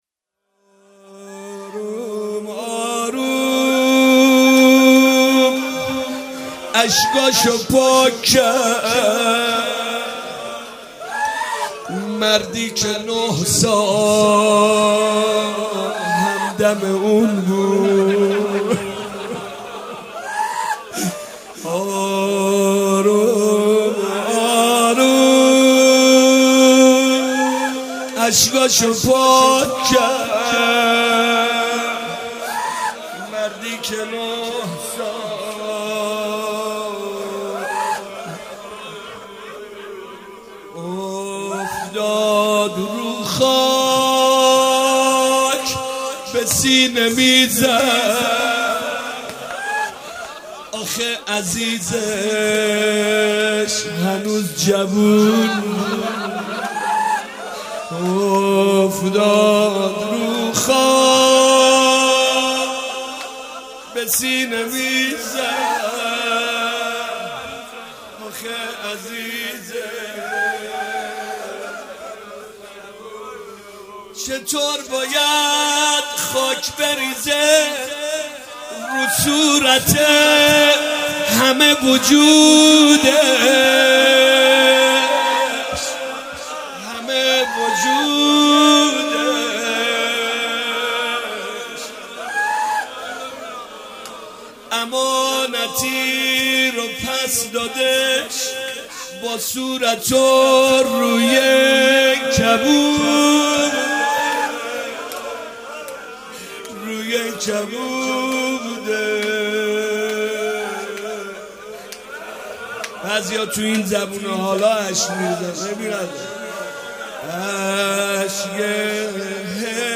فاطمیه 95 - شب پنجم - روضه - آروم آروم اشکاشو پاک کرد